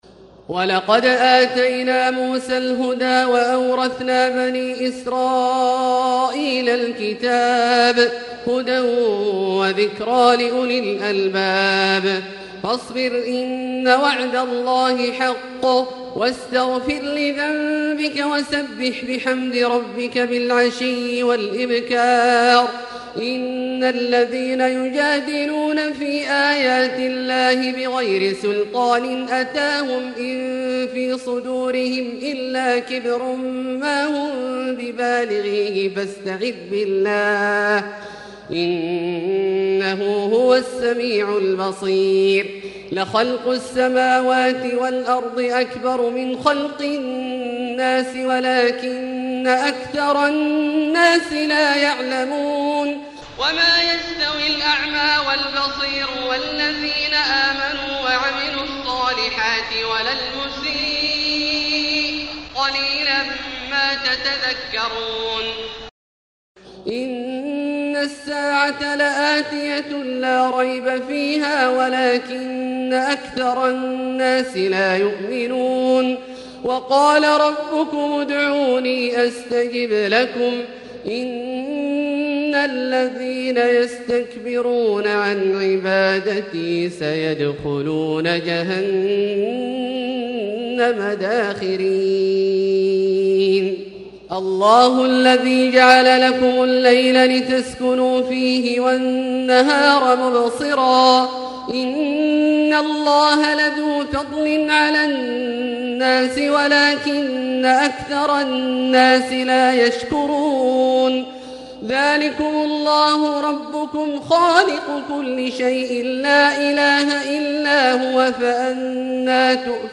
تراويح ليلة 23 رمضان 1440هـ من سور غافر (53-85) وفصلت (1-46) Taraweeh 23 st night Ramadan 1440H from Surah Ghaafir and Fussilat > تراويح الحرم المكي عام 1440 🕋 > التراويح - تلاوات الحرمين